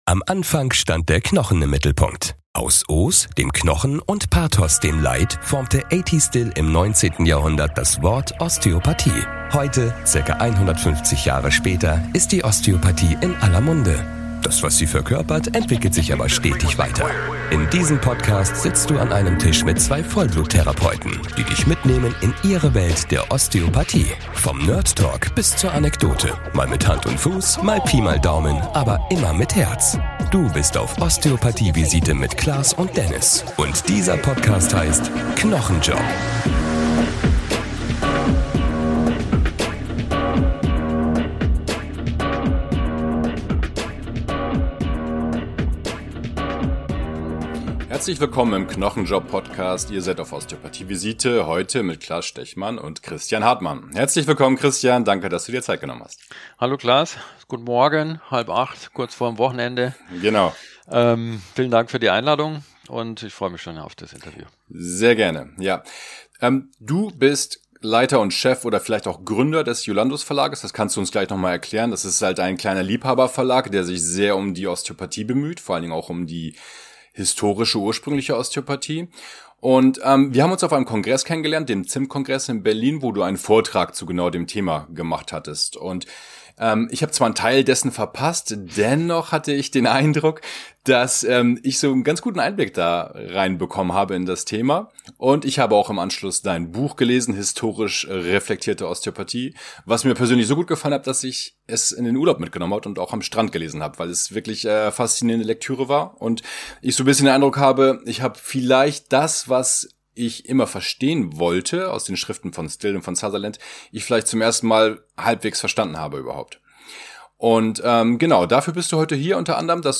Interview-Spezial